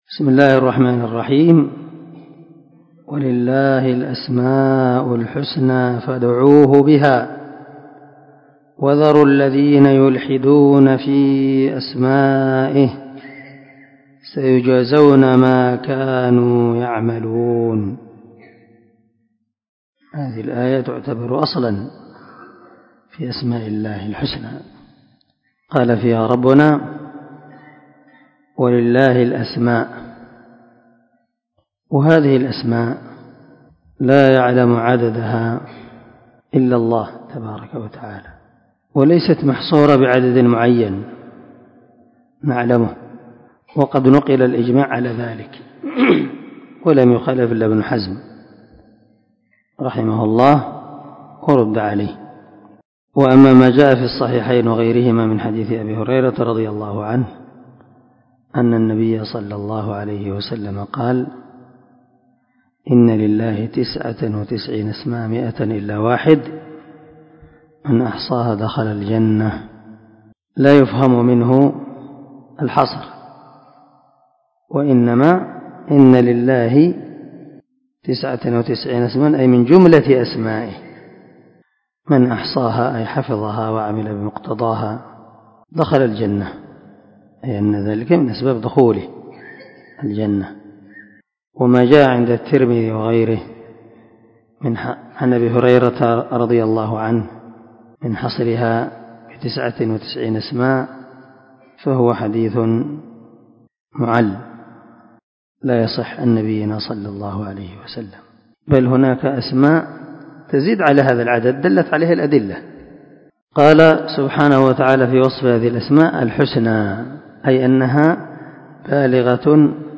495الدرس 47تفسير آية ( 180 ) من سورة الأعراف من تفسير القران الكريم مع قراءة لتفسير السعدي
دار الحديث- المَحاوِلة- الصبيحة.